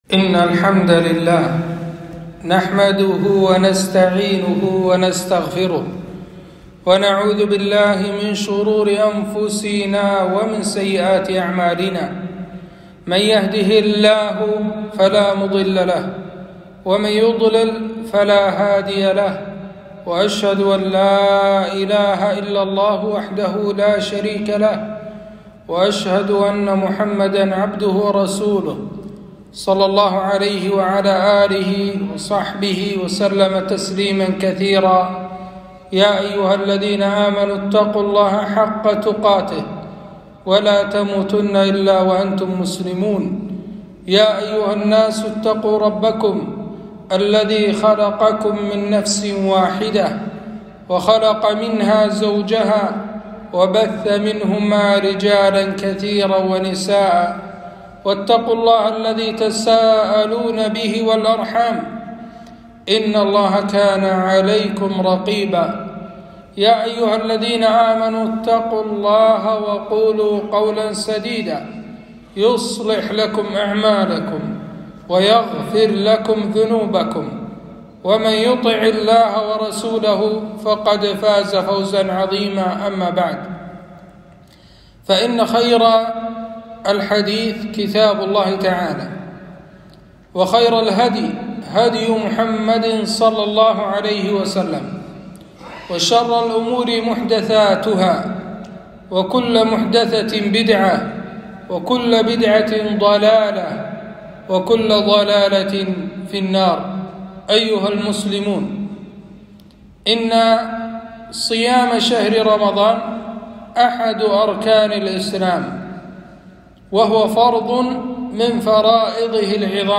خطبة - الصيام آداب وأحكام